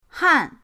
han4.mp3